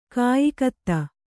♪ kāyikatta